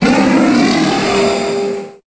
Fichier:Cri 0791 EB.ogg — Poképédia
Cri_0791_EB.ogg